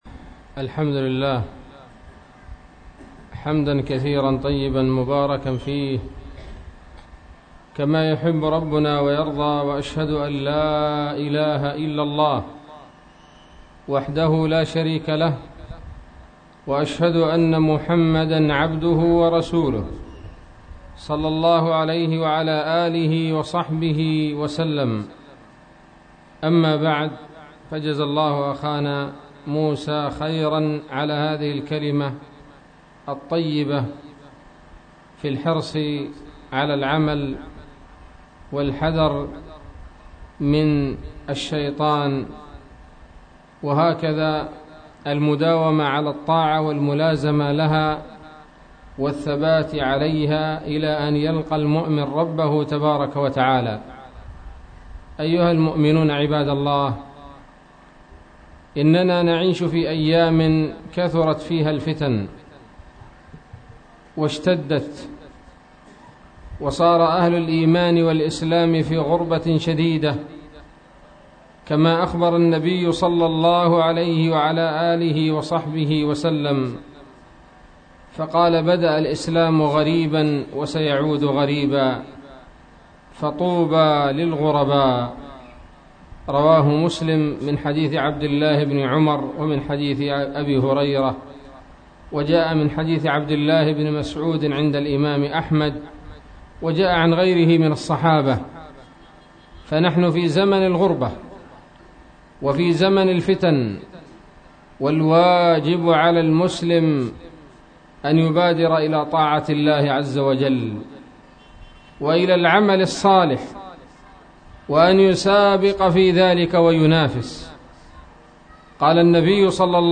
محاضرة بعنوان :((المبادرة الى طاعة الله